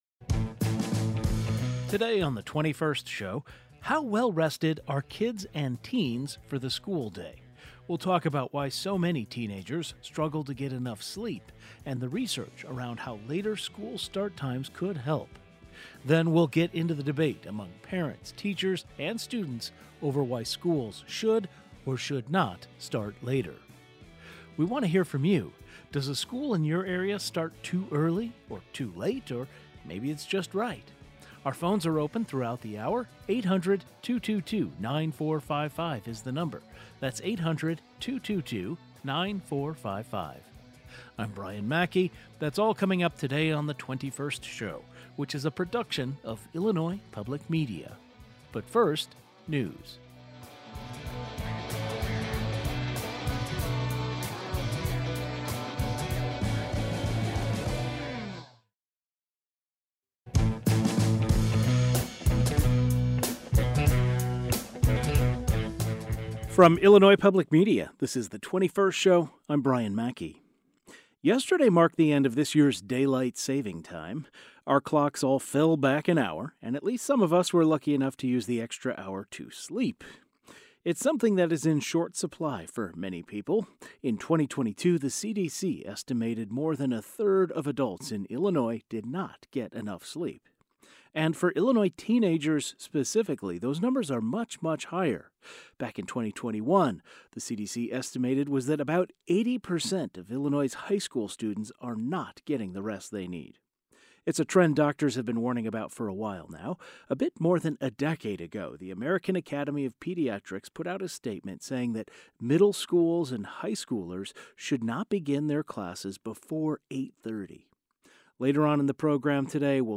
A panel of experts involved in the field of education across the state discuss why school start times are what they are, and whether they should, or even could, change. Some sleep experts weigh in as well. The 21st Show is Illinois' statewide weekday public radio talk show, connecting Illinois and bringing you the news, culture, and stories that matter to the 21st state.